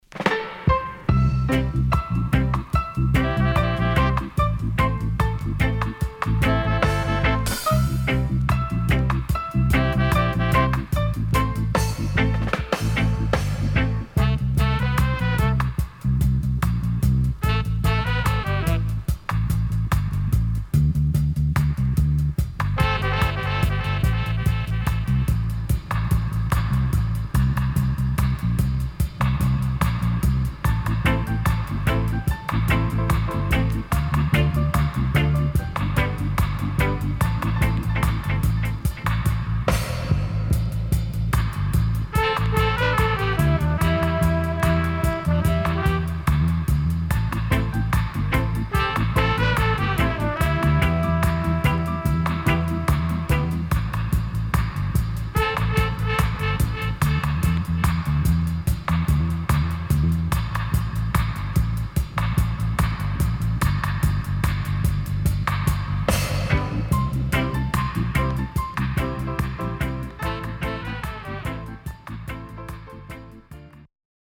SIDE B:少しプチノイズ入ります。